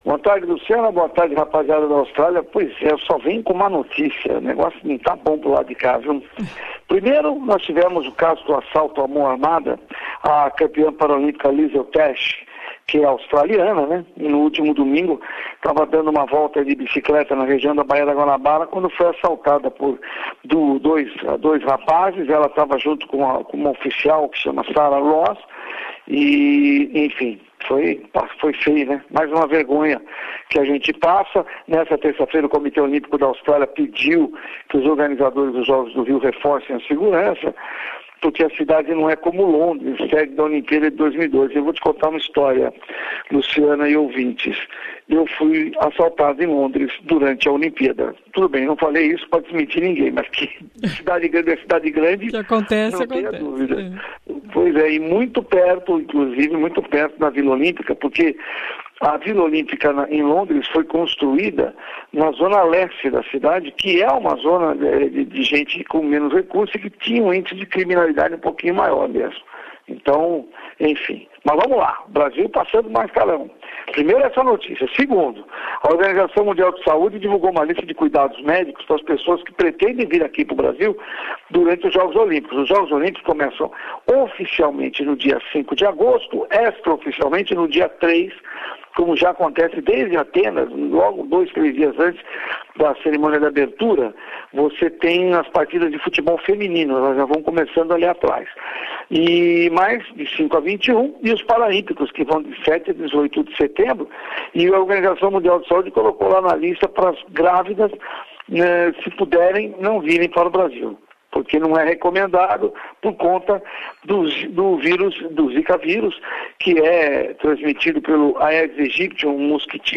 sports bulletin